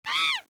hit_01.ogg